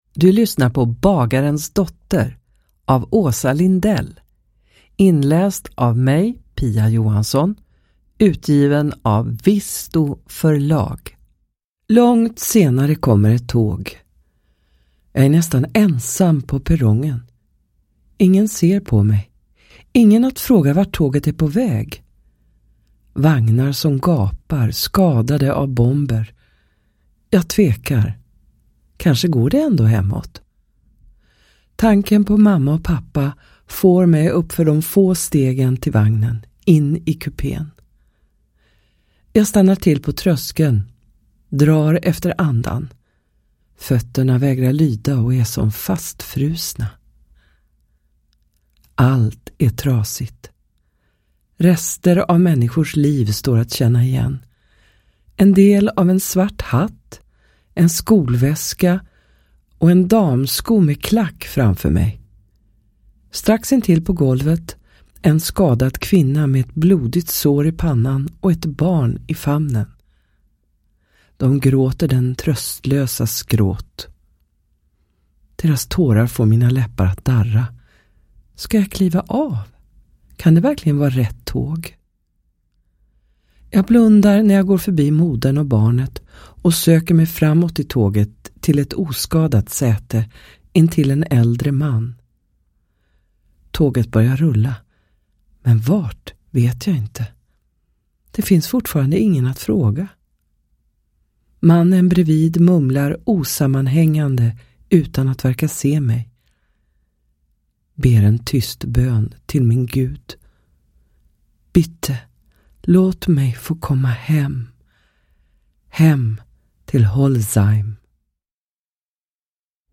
Bagarens dotter / Ljudbok